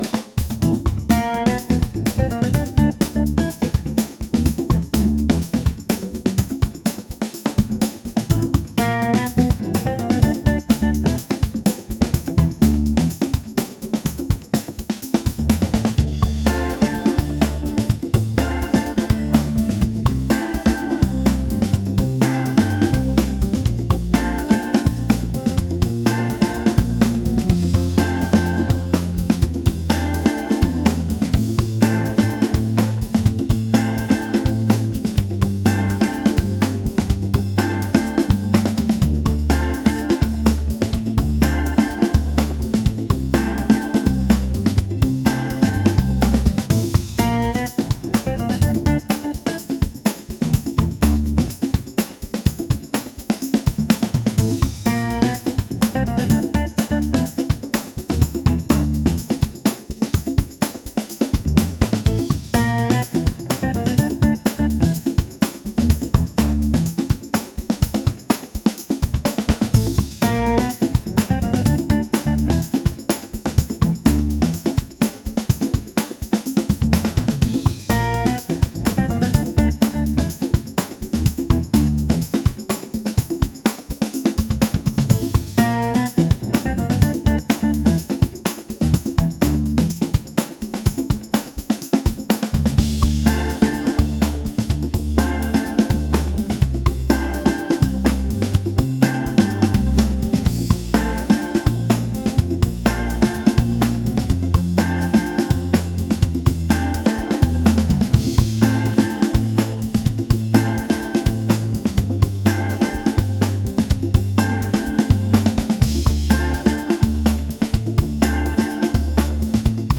funky | fusion | jazz